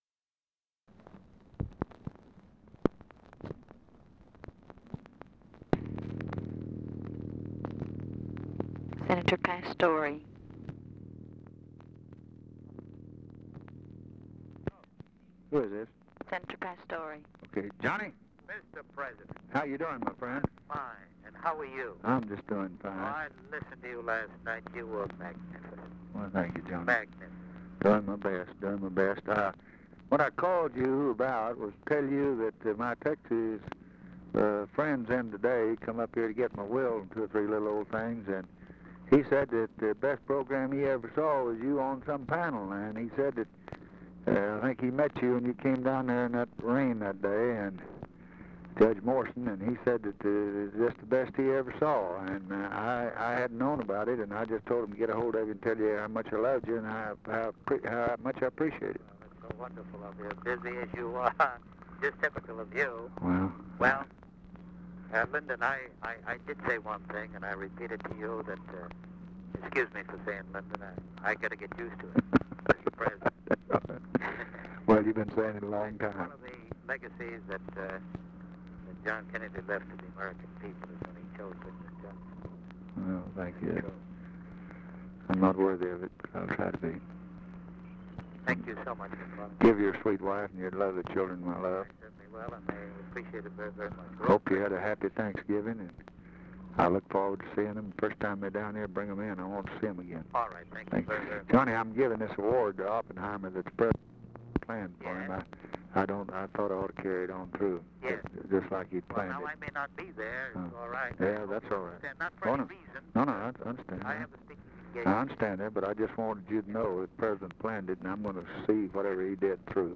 Conversation with JOHN PASTORE, November 29, 1963
Secret White House Tapes